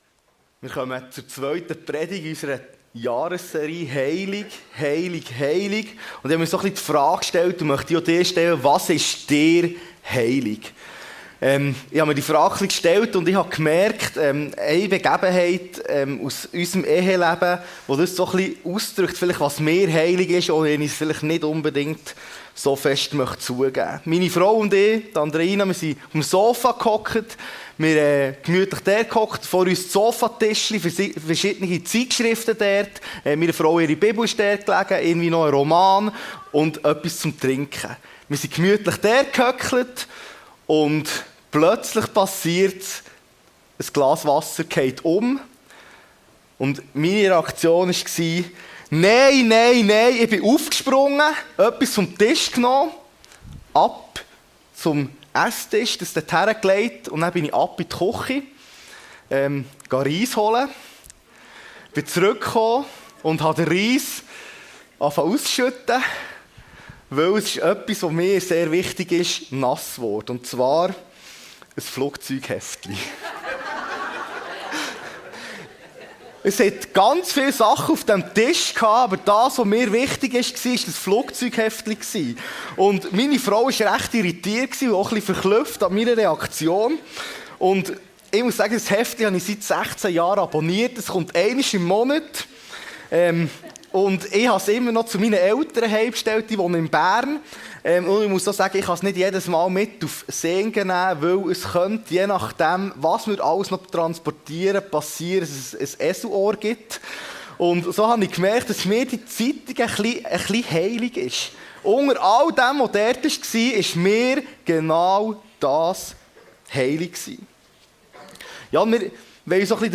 Gottes heiliges Volk – seetal chile Predigten